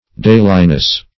Dailiness \Dai"li*ness\, n. Daily occurence.